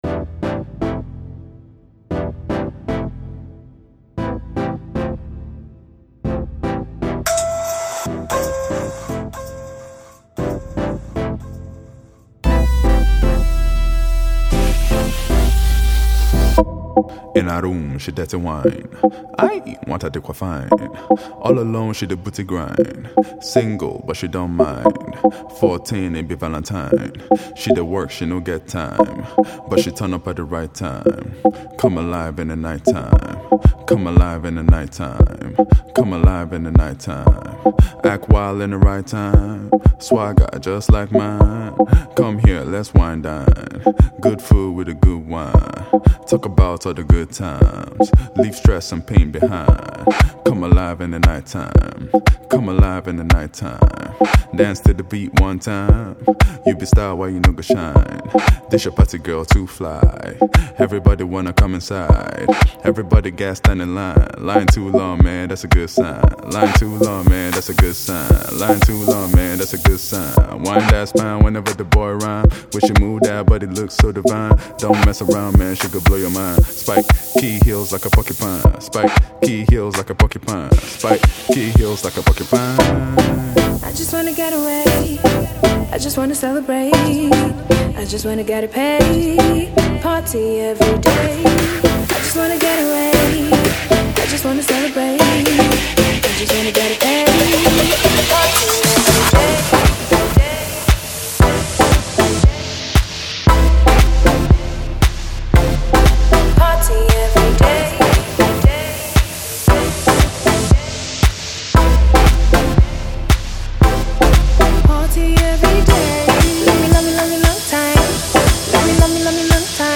feel good song